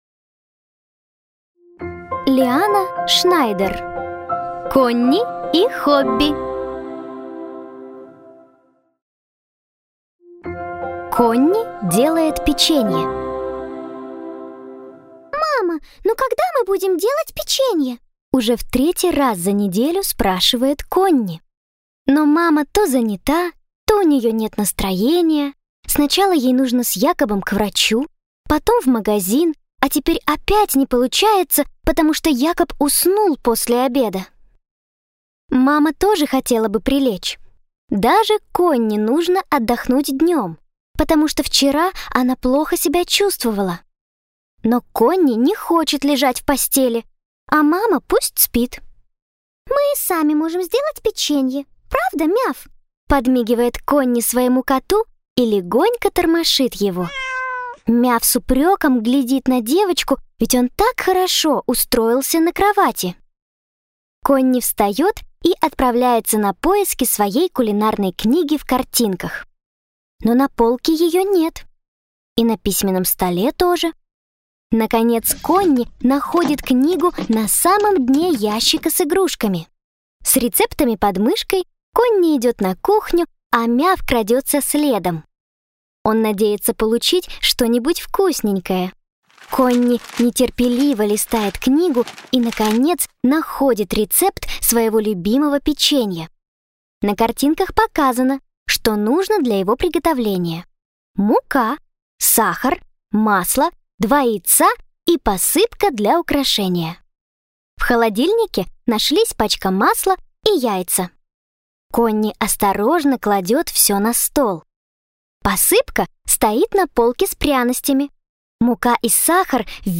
Аудиокнига Конни и хобби. Сборник историй | Библиотека аудиокниг
Прослушать и бесплатно скачать фрагмент аудиокниги